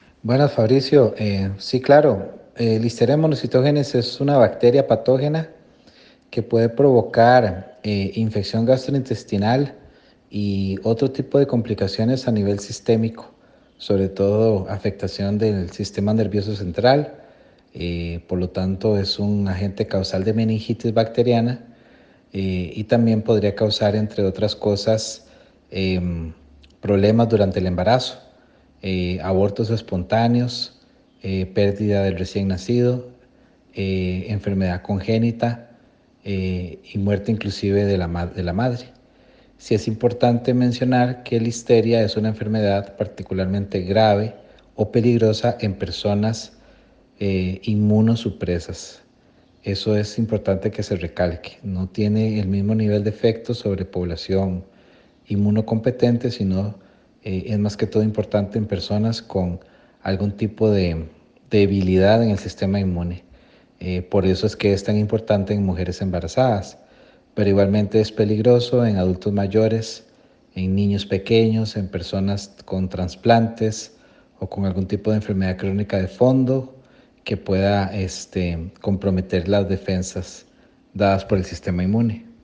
La voz del especialista